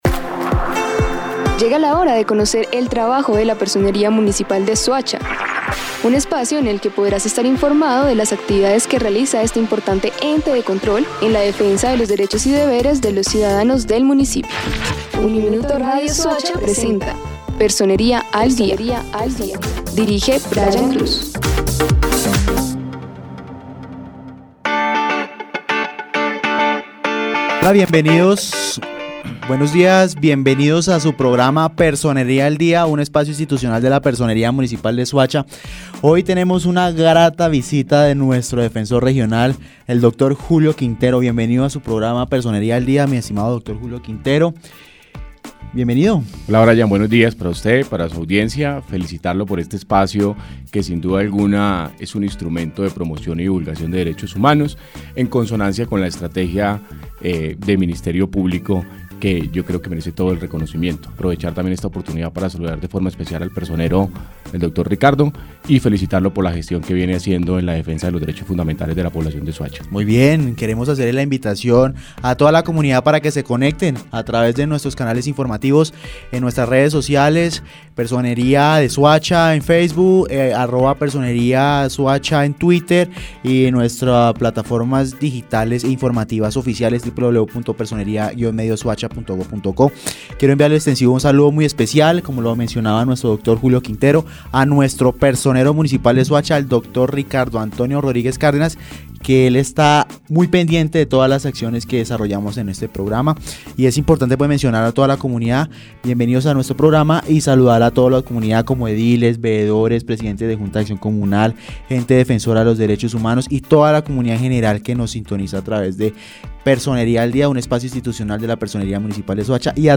El Defensor Regional de Cundinamarca, Julio Quintero, abogado especialista en derecho penal de la Universidad el Rosario, habló en Uniminuto Radio Soacha y su programa Personería al Día sobre las…